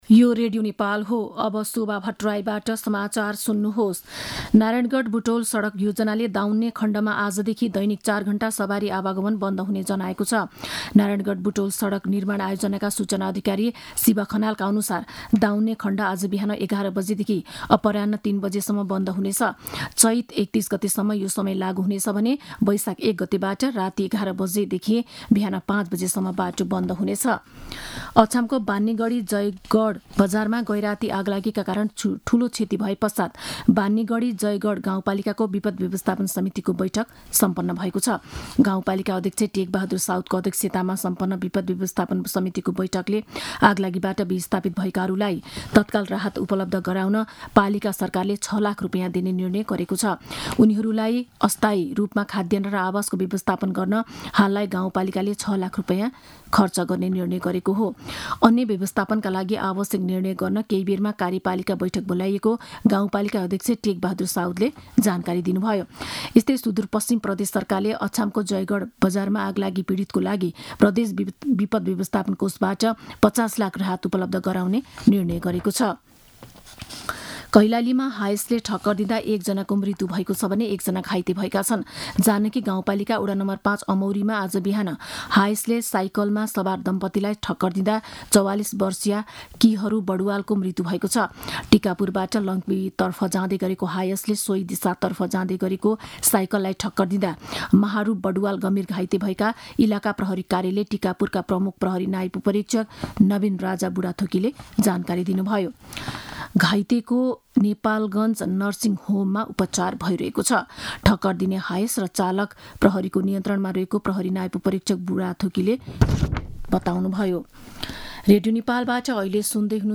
मध्यान्ह १२ बजेको नेपाली समाचार : २७ चैत , २०८१